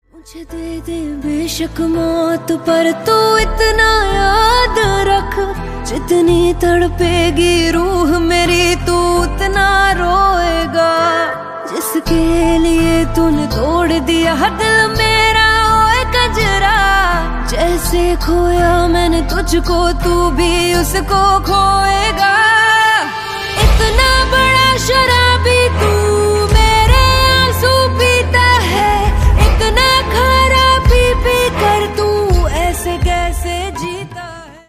Hindi Songs
haunting melody